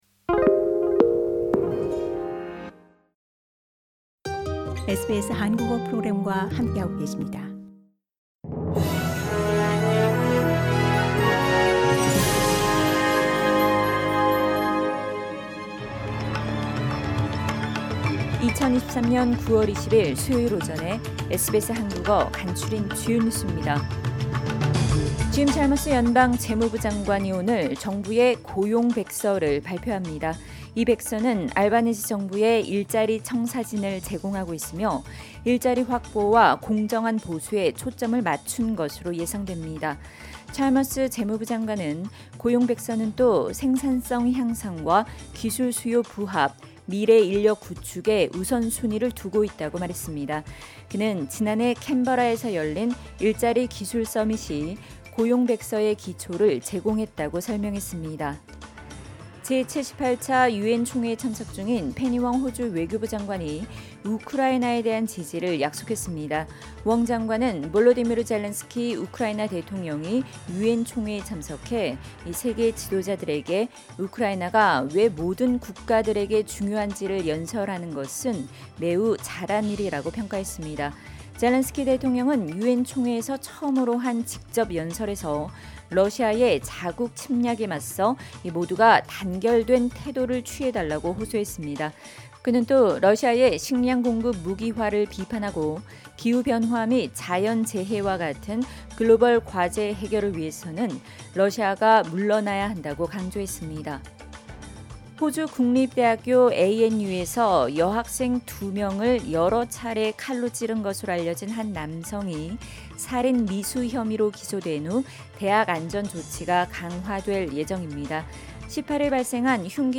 SBS 한국어 아침 뉴스: 2023년 9월20일 수요일